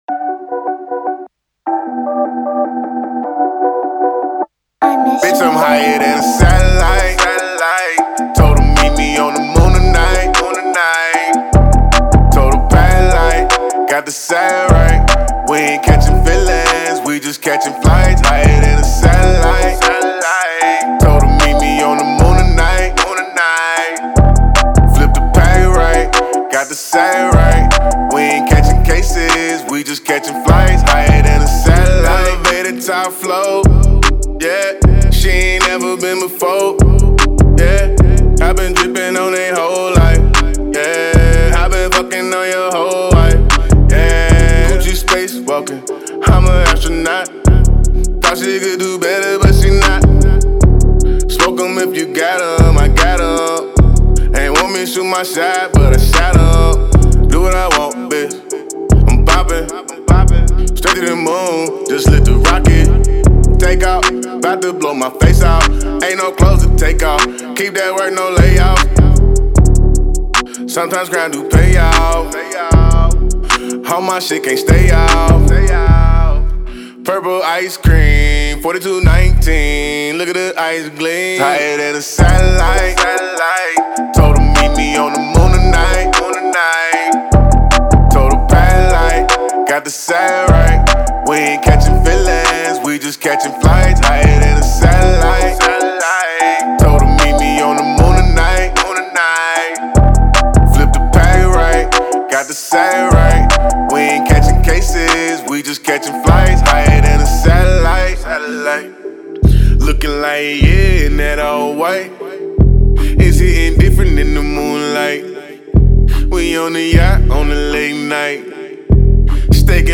Hiphop
the melodic and mesmerizing track